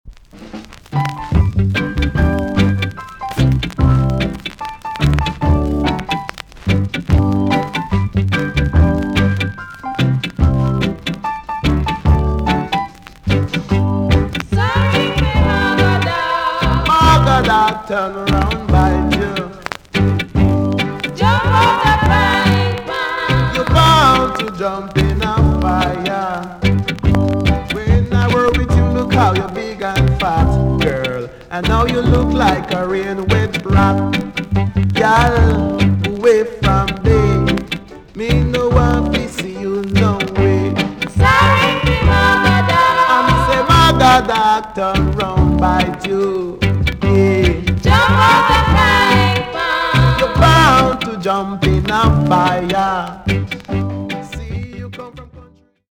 TOP >REGGAE & ROOTS
VG+ 少し軽いチリノイズがあります。